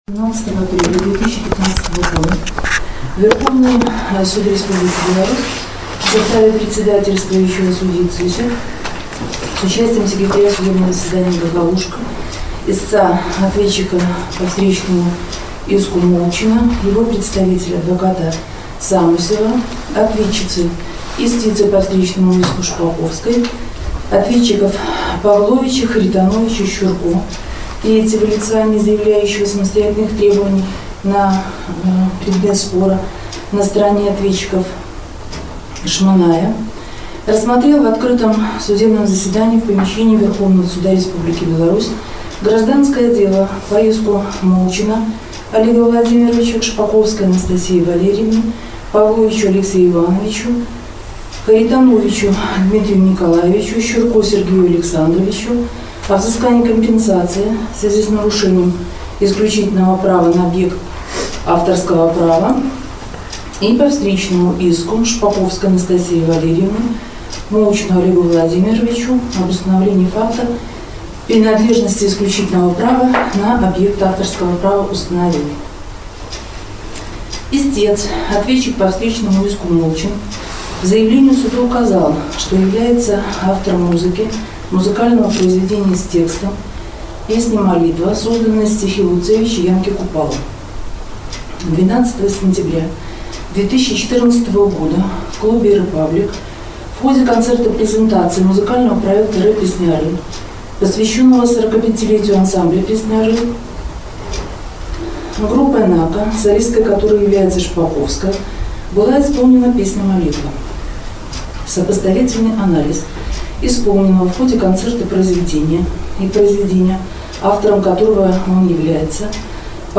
Сваё рашэньне судзьдзя Іна Цысік чытала 40 хвілінаў.